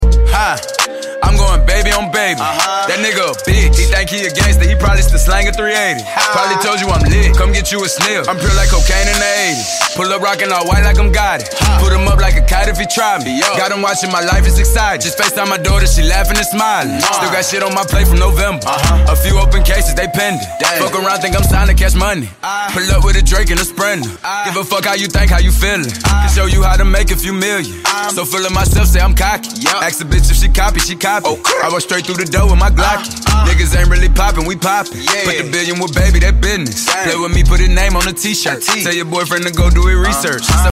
Ringtones Category: Rap - Hip Hop